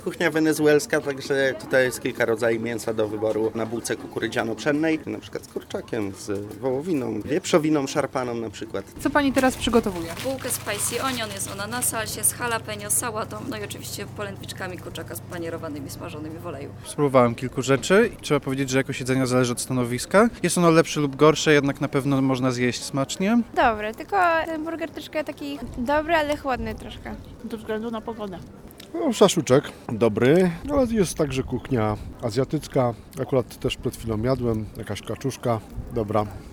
Są opcje na przykład z kurczakiem, z wołowiną szarpaną wieprzowiną – mówi jeden z wystawców.